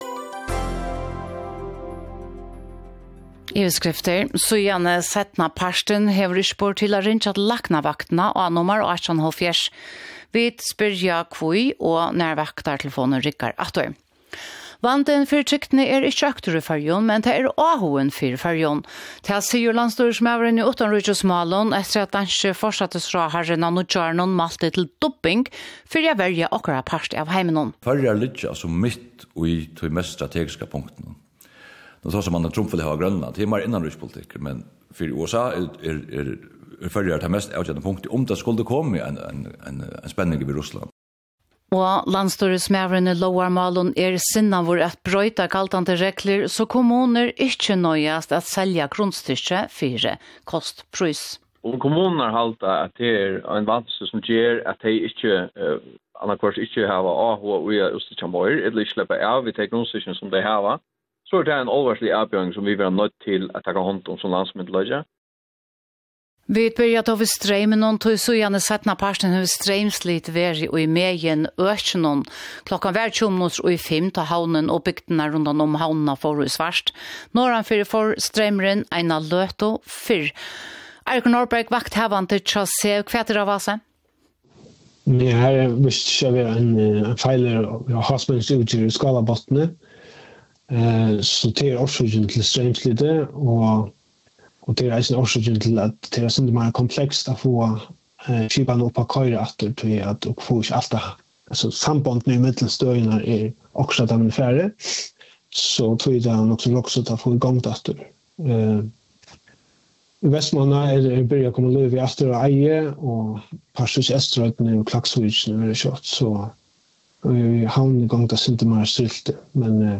… continue reading 50 ตอน # faroe islands # News # Kringvarp Føroya